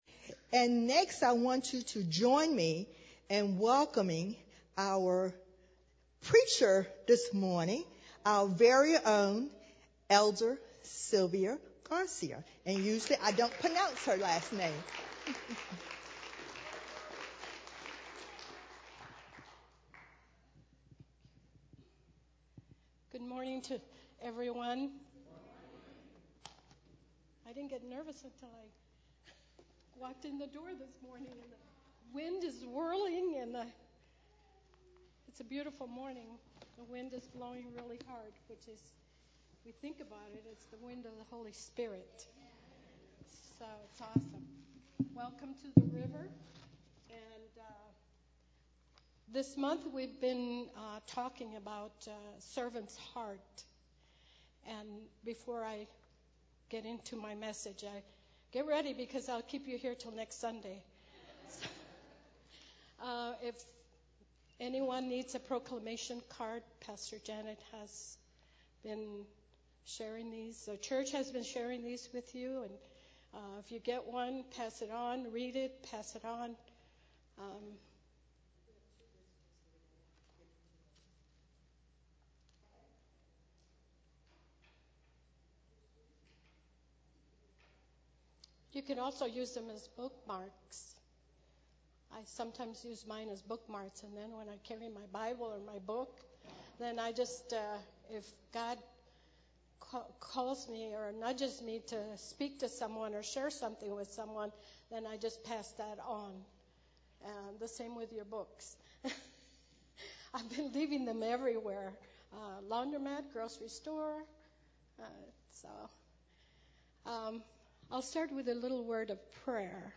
Sermons Archive - River of Life Community Church